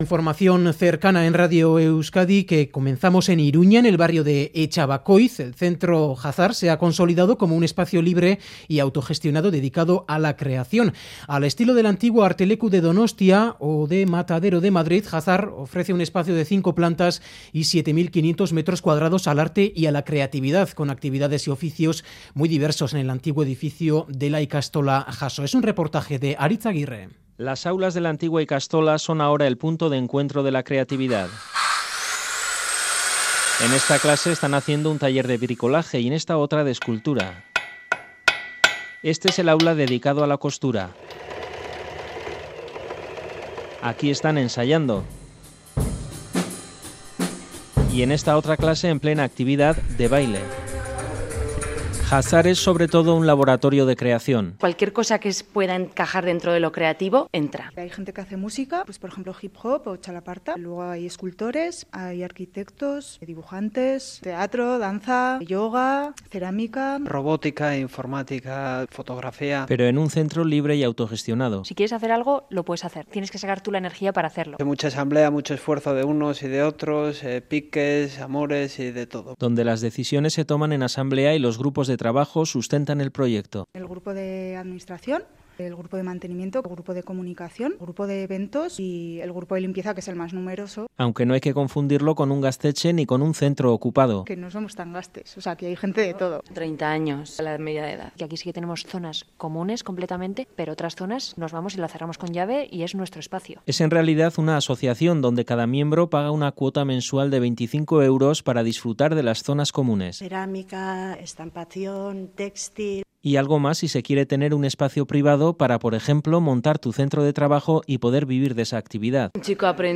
Radio Euskadi REPORTAJES Se consolida en Iruña el laboratorio de creación libre y autogestionado Última actualización: 30/11/2017 13:53 (UTC+1) Es un centro de 7.500 metros cuadrados dedicado al arte y a la creatividad, con oficios muy diversos.